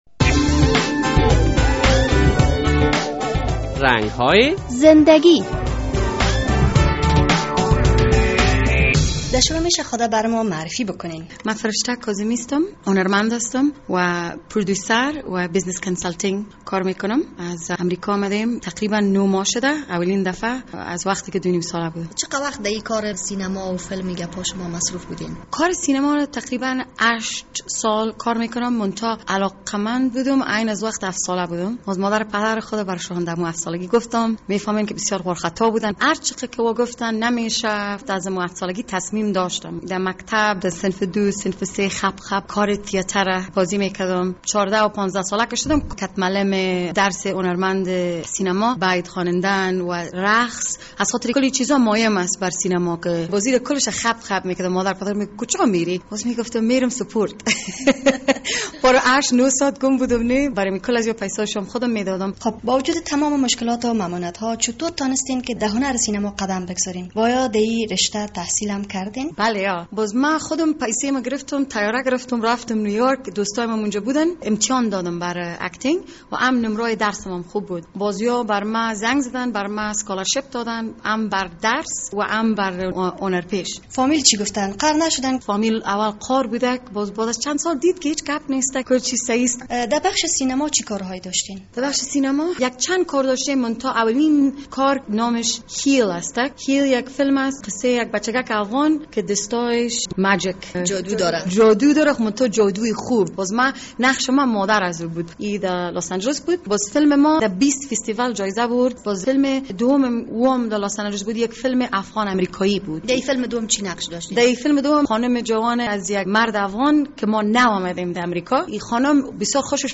در این برنامهء رنگ های زندگی با یک هنرمند سینما صحبت شده است